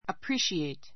appreciate A2 əpríːʃieit ア プ リ ーシエイ ト 動詞 ❶ （真価などを） 十分に理解する; （芸術などを） 鑑賞 かんしょう する, 味わう Many foreigners can appreciate Kabuki.